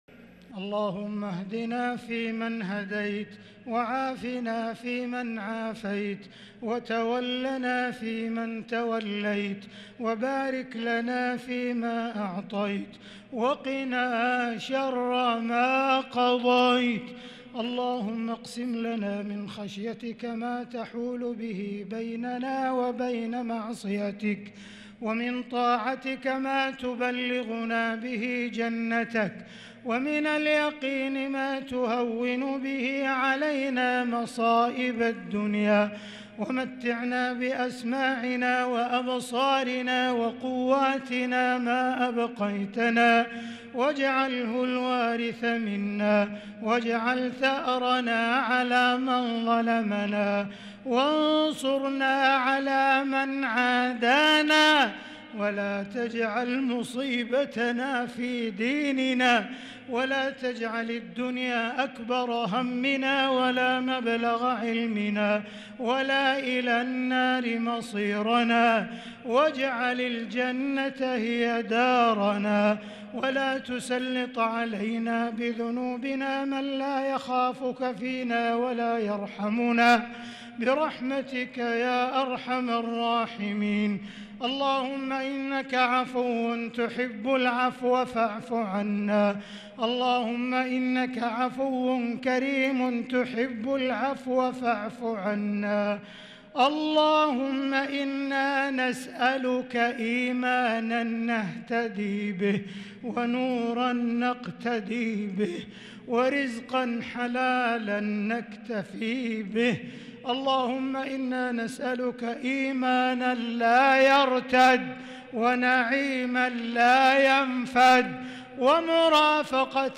دعاء القنوت ليلة 19 رمضان 1443هـ | Dua for the night of 19 Ramadan 1443H > تراويح الحرم المكي عام 1443 🕋 > التراويح - تلاوات الحرمين